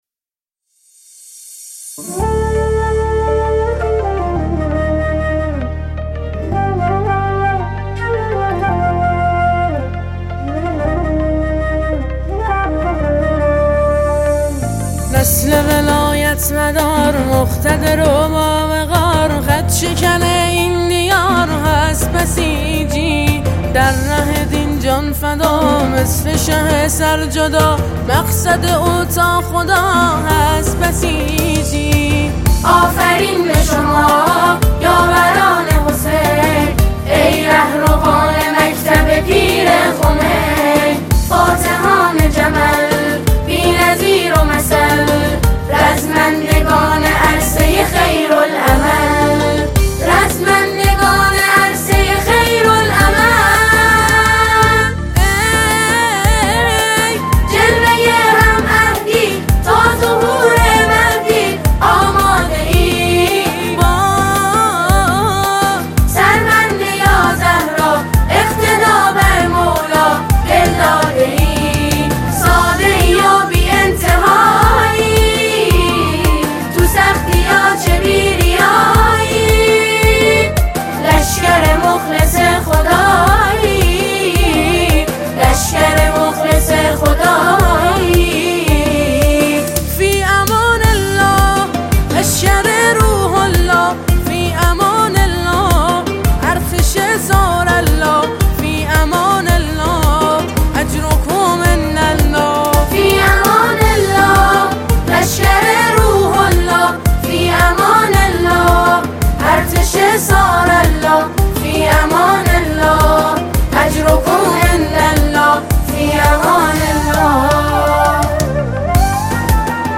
سرود ، سرود انقلابی ، سرود ملی و مذهبی ، سرود مناسبتی